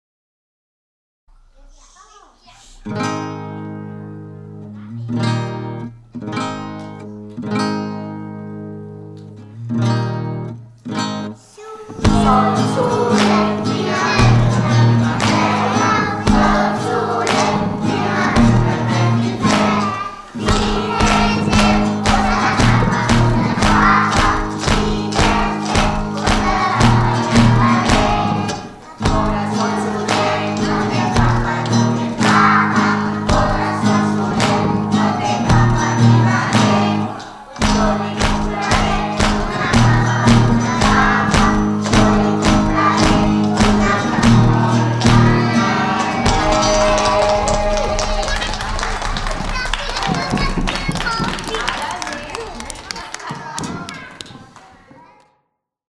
gravar una cançó entre tots